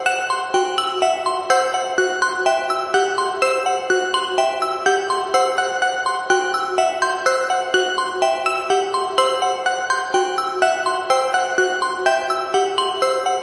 通风管道爬行
描述：模仿某人在通风管道爬行的声音
标签： 黑暗 回声 金属 通风 空气 混响 沙沙 管道 碰撞
声道单声道